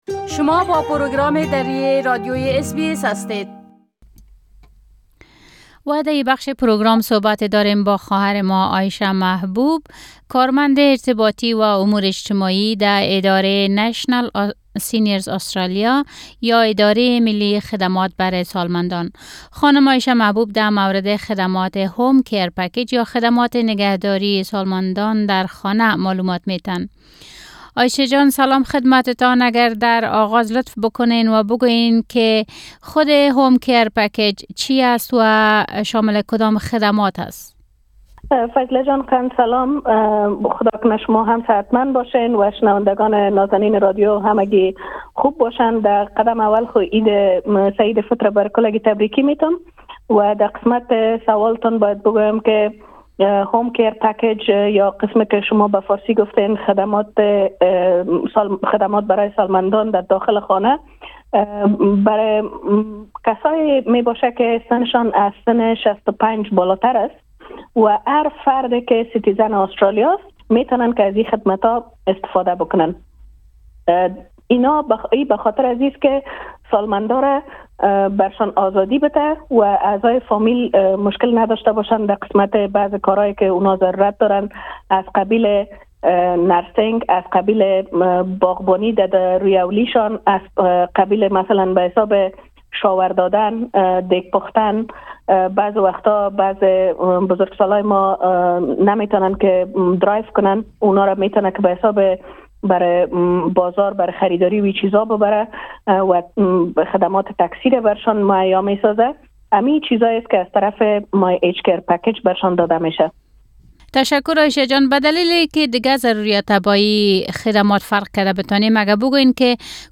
مصاحبه